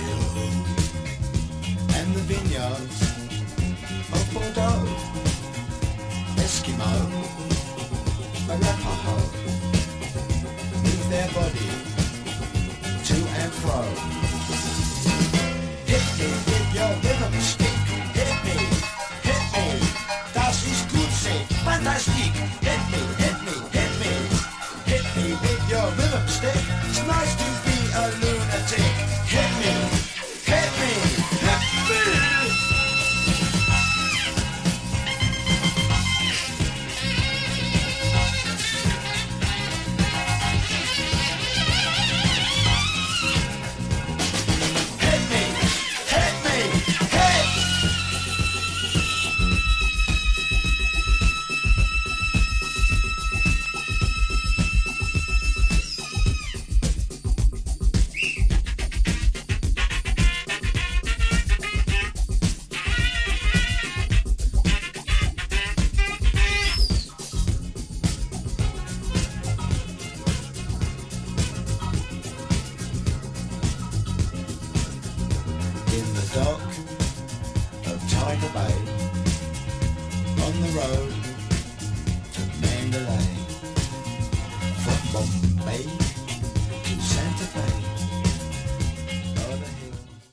'79年パブロック・ディスコ、ロフト/ガラージ定番ダンス・クラシック・マスターピース！！